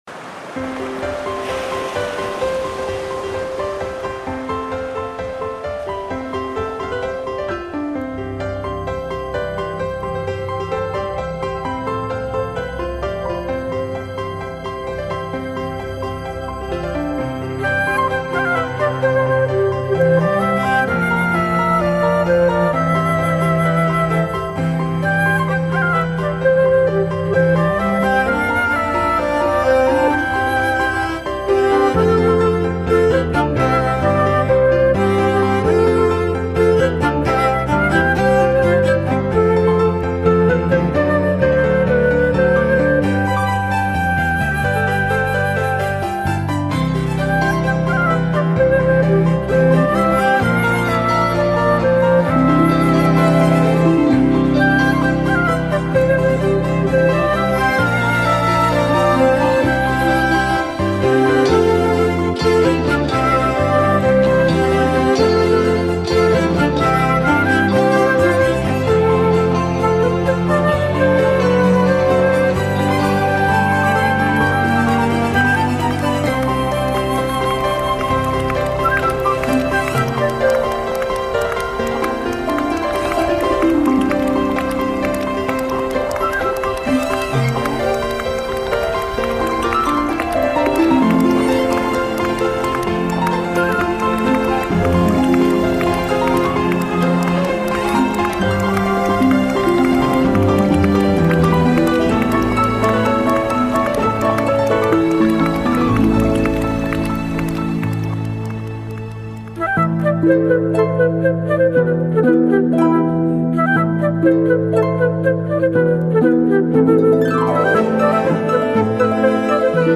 鯨豚鳴唱。燕鷗高歌。黑面琵鷺來去啁啾。
第一張紀錄海洋生命氣息的音樂專輯
★ 感性享受--金曲獎製作群精心創作，10首自然音樂帶來10種海洋感動